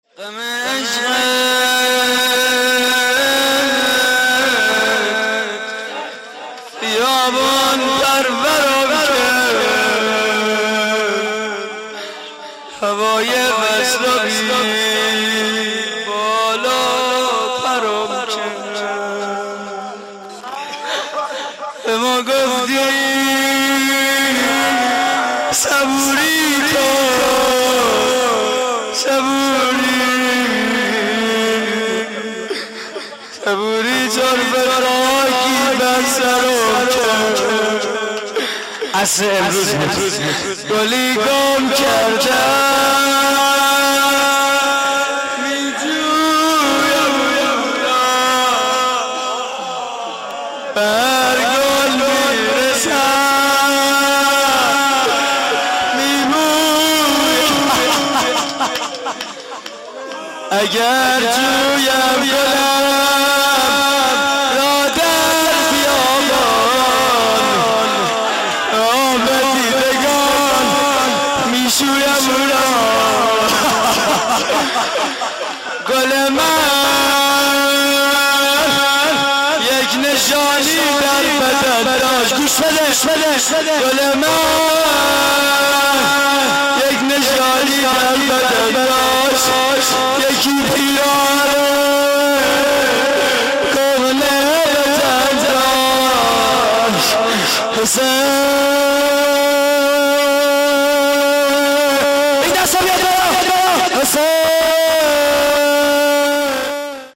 روضه پایانی: گلی گم کرده‌ام؛ پخش آنلاین |